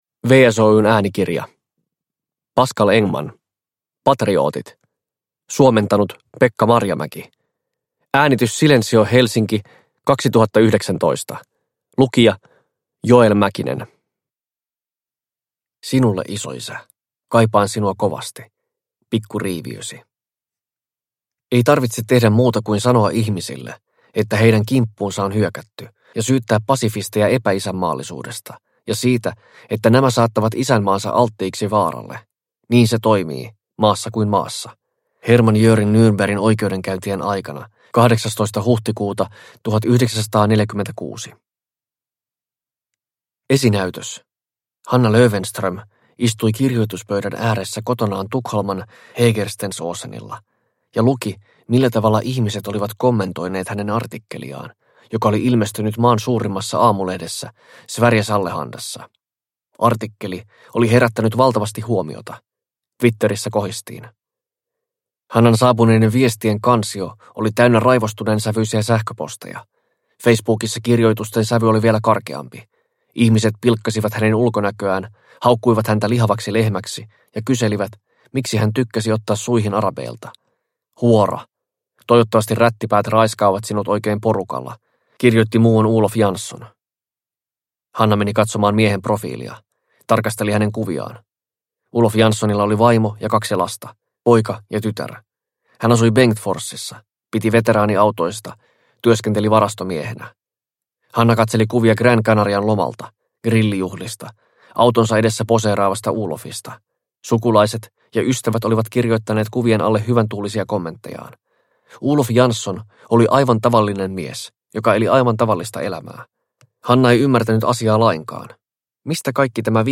Patriootit – Ljudbok – Laddas ner